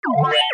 Déplacement 3.mp3